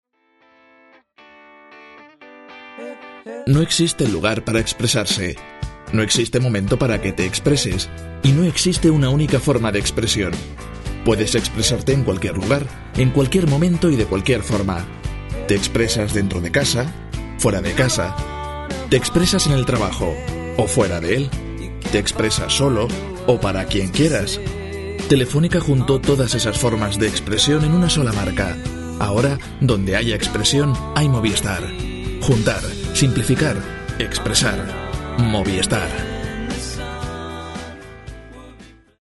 Si está buscando una voz joven, seria, amable y cálida para su proyecto de locución, yo puedo ofrecerle un servicio de calidad, rápido y económico.
Sprechprobe: Sonstiges (Muttersprache):
Castilian accent (native voice talent from Spain, living in Madrid), ideal if you are looking for an european spanish accent or if your target market is Spain. Warm, deep and sensual for commercial and promos; warm, deep and serious for institutional promos, presentations, etc. Younger voice for other kind of projects.